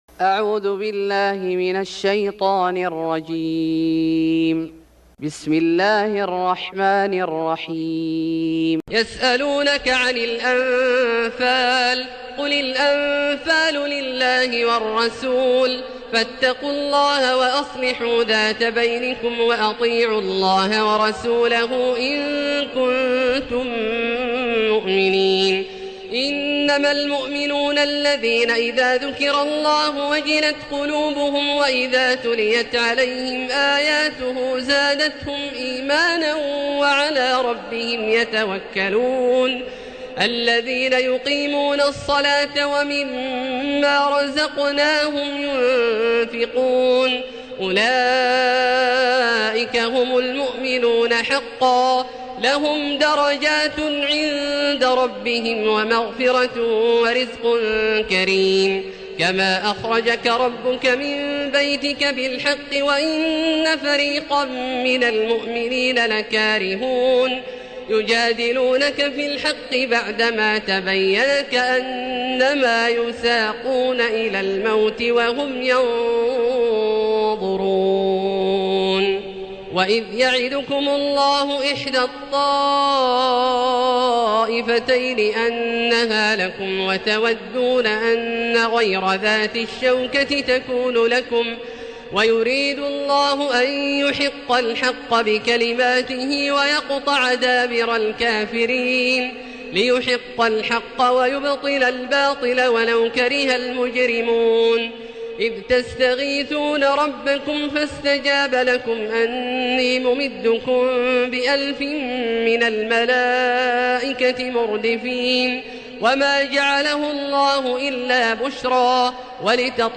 سورة الأنفال Surat Al-Anfal > مصحف الشيخ عبدالله الجهني من الحرم المكي > المصحف - تلاوات الحرمين